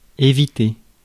France (Paris)